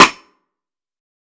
TC3Snare11.wav